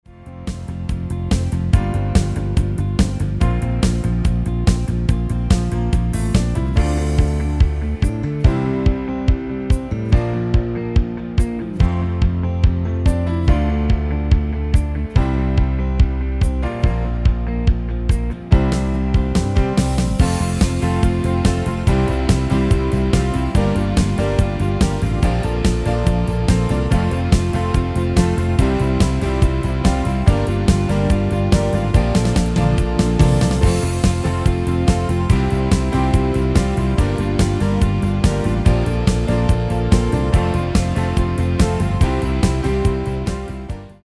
Extended MIDI File Euro 11.75
Demo's played are recordings from our digital arrangements.